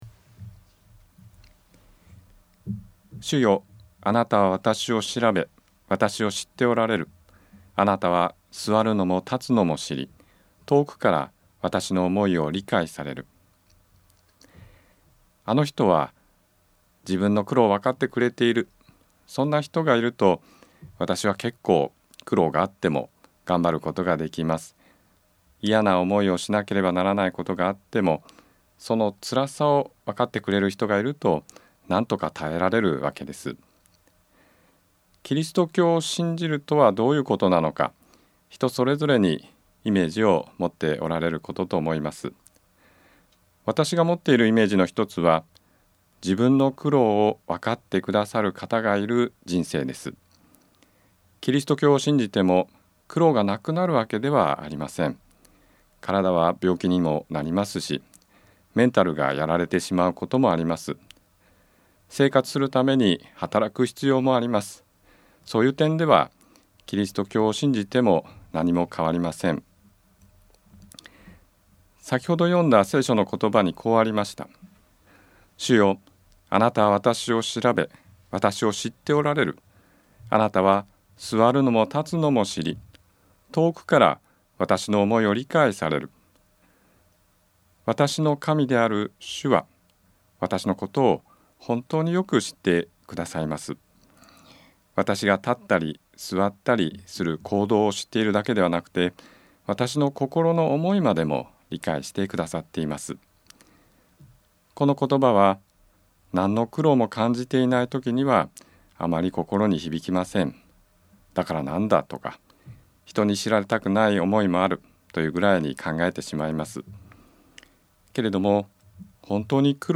聖書は詩編より ラジオ番組「キリストへの時間」